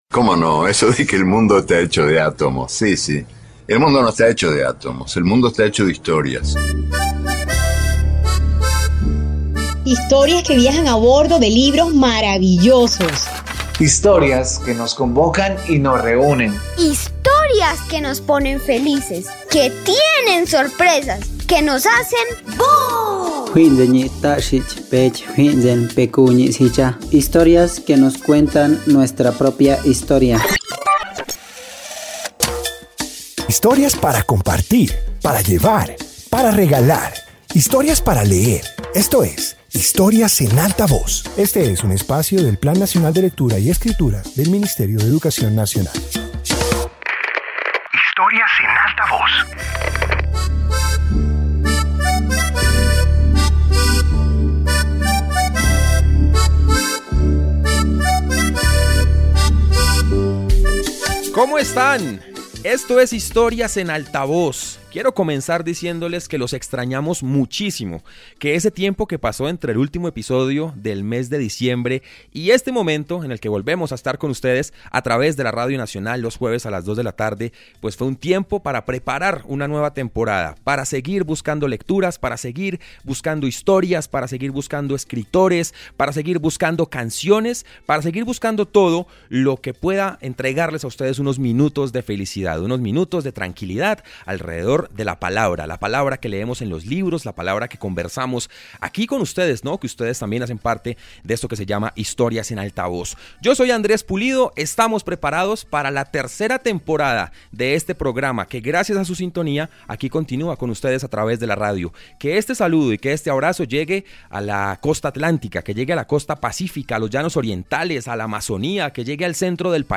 Este episodio de radio comparte experiencias y memorias relacionadas con leer en voz alta. Presenta situaciones familiares, momentos de cercanía y formas de disfrutar los libros mientras se fortalecen vínculos afectivos y se estimula la imaginación.